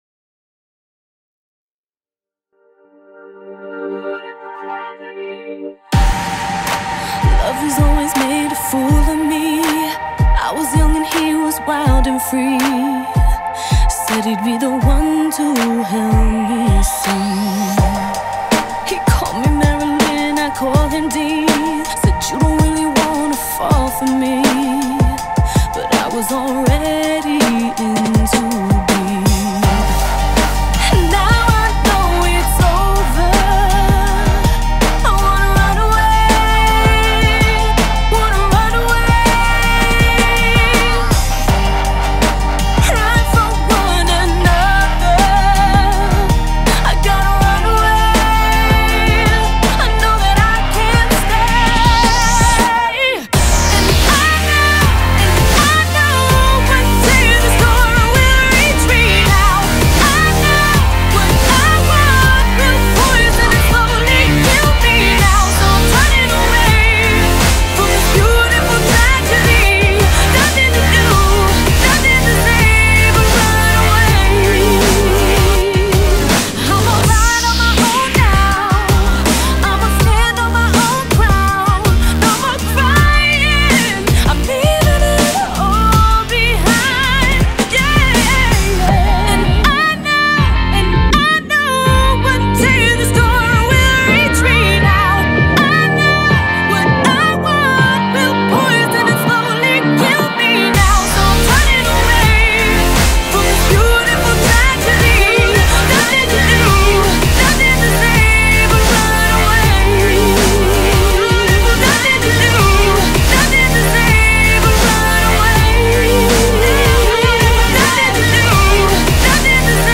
BPM41-81
Audio QualityCut From Video